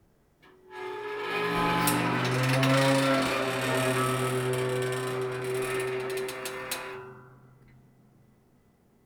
metal_creak2.wav